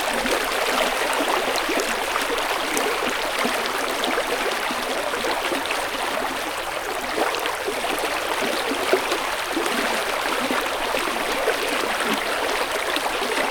river2.ogg